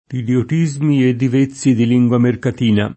mercatino
mercatino [ merkat & no ]